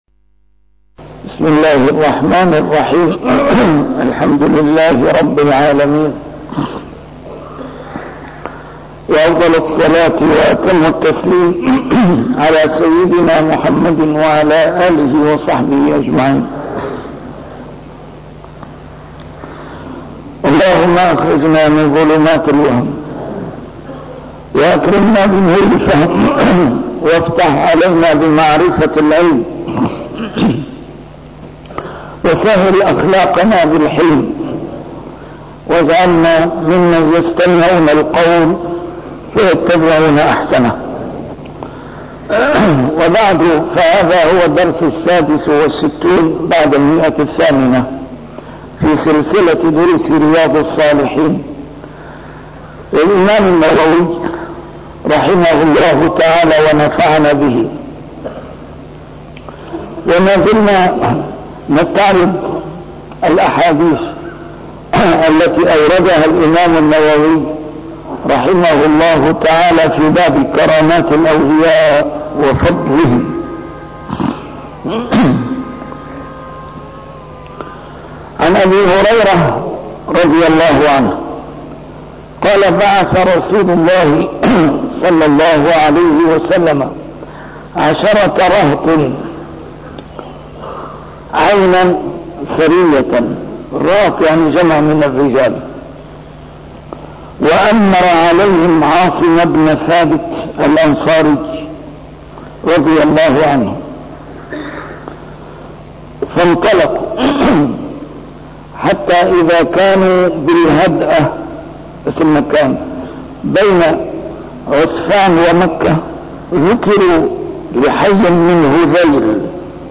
A MARTYR SCHOLAR: IMAM MUHAMMAD SAEED RAMADAN AL-BOUTI - الدروس العلمية - شرح كتاب رياض الصالحين - 866- شرح رياض الصالحين: كرامات الأولياء وفضلهم